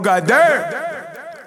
TS - CHANT (18).wav